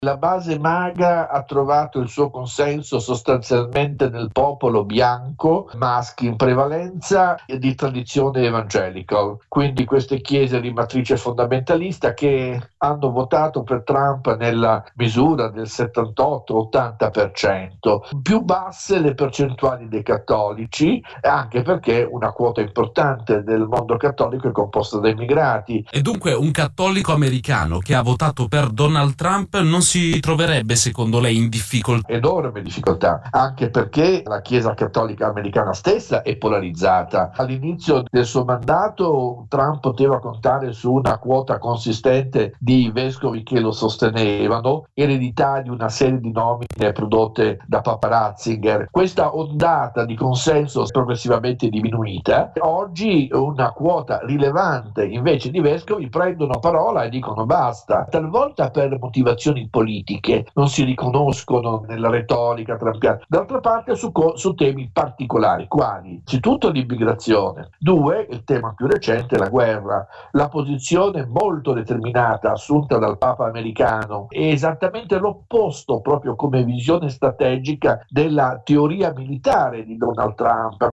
La risposta di Prevost è stata molto ferma: "Non ho paura di Trump" e "continuerò a parlare ad alta voce contro la guerra". Il politologo